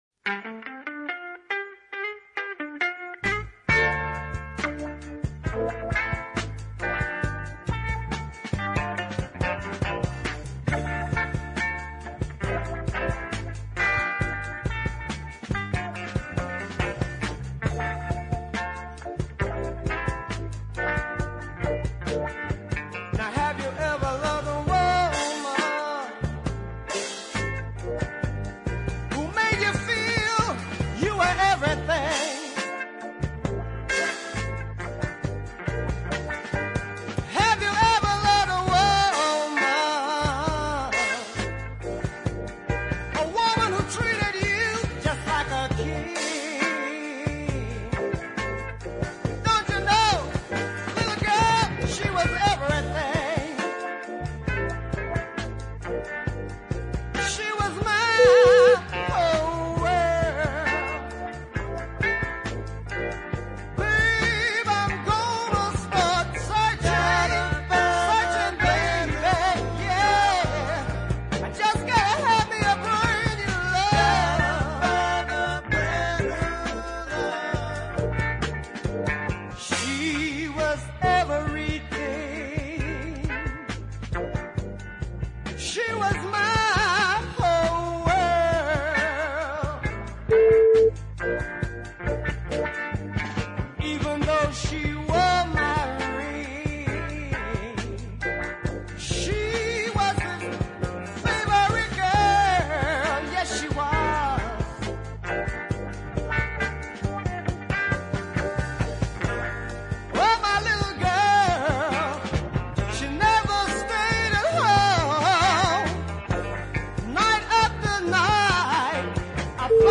small band ballads of both power and presence
minor keyed